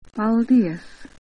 paldies /pal’dies/ – from the Latvian lads who delivered and installed my new garden shed.